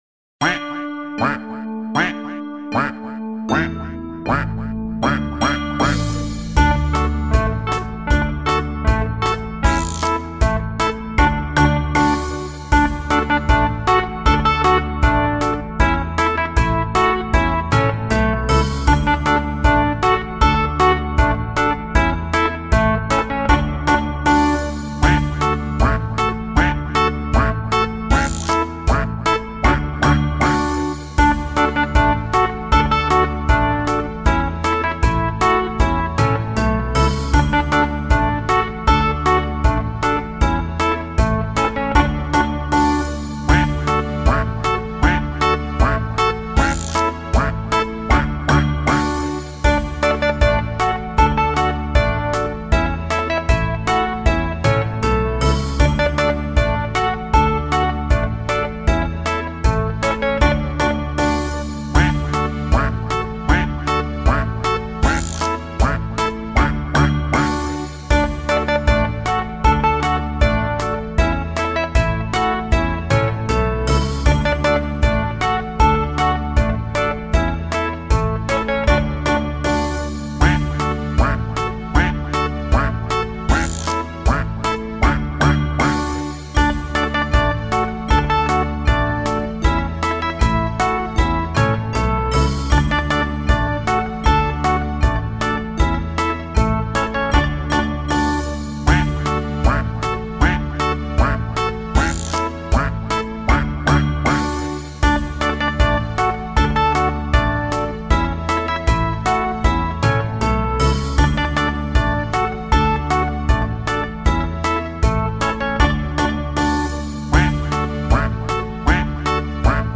Canción infantil, EE.UU.